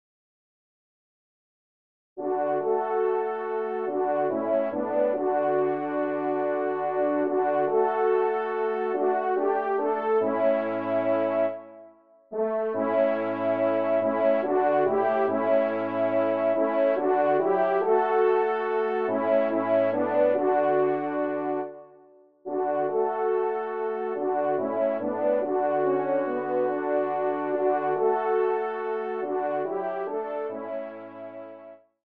Genre :  Musique religieuse pour Trompes ou Cors
ENSEMBLE